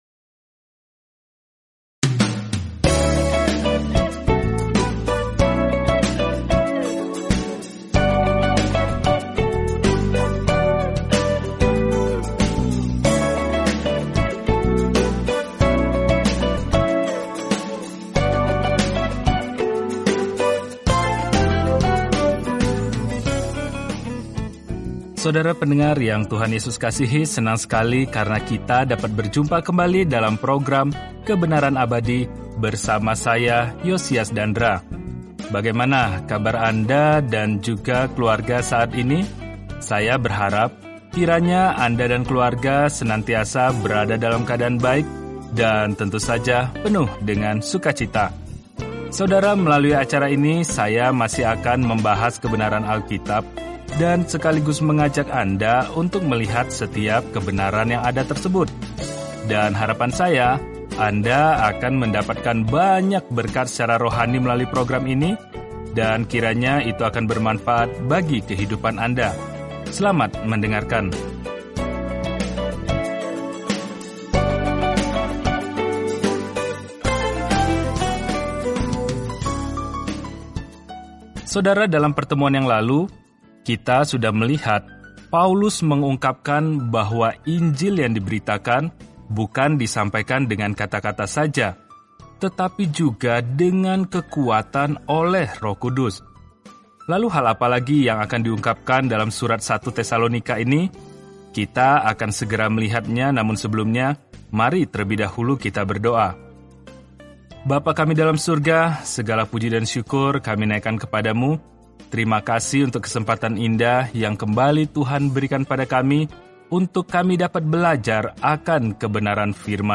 Jelajahi 1 Tesalonika setiap hari sambil mendengarkan pelajaran audio dan membaca ayat-ayat tertentu dari firman Tuhan.